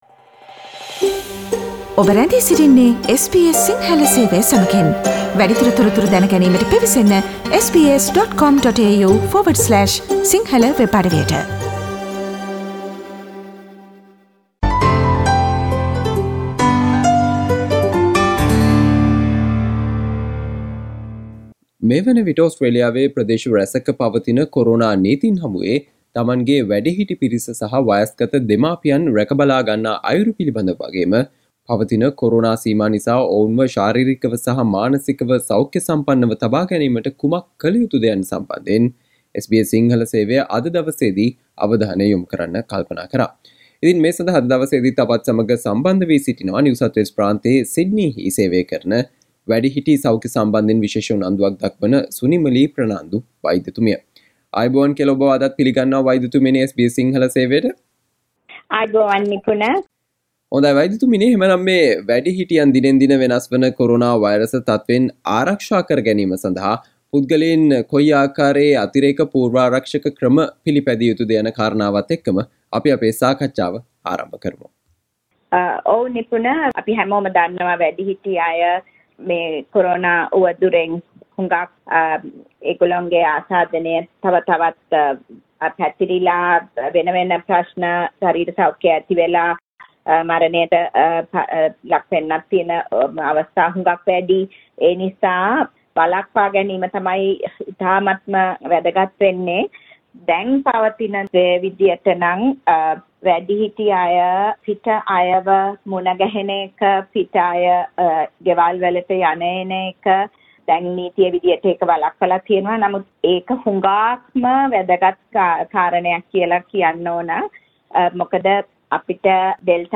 මෙම කොරෝනා වසංගත සමය සහ නීති අතරතුර ඕස්ට්‍රේලියාවේ සිටින තමන්ගේ වැඩිහිටි පිරිස සහ වයස්ගත දෙමාපියන් රැකබලාගන්නා අයුරු සහ ඔවුන්ව ශාරීරිකව හා මානසිකව සෞඛ්‍ය සම්පන්නව තබා ගැනීමට කුමක් කළ යුතුද යන්න පිළිබඳව SBS සිංහල සේවය සිදු කල සාකච්චාවට සවන්දෙන්න